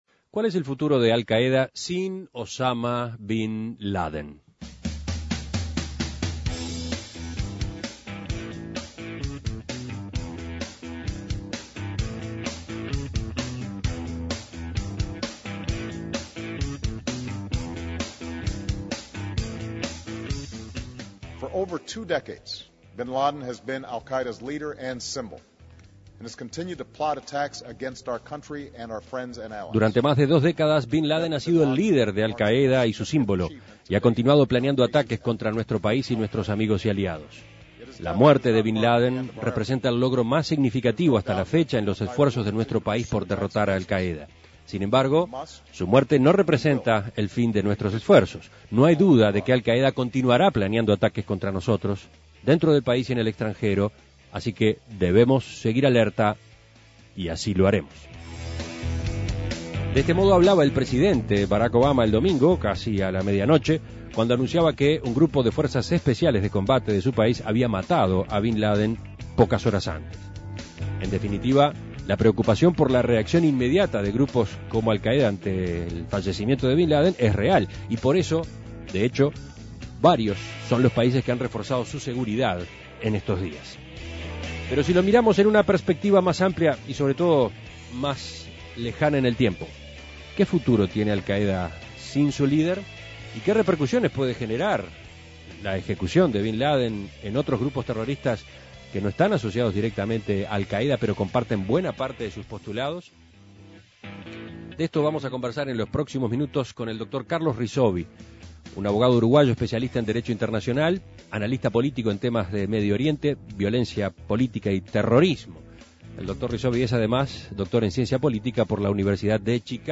analista político en temas de Medio Oriente, violencia política y terrorismo.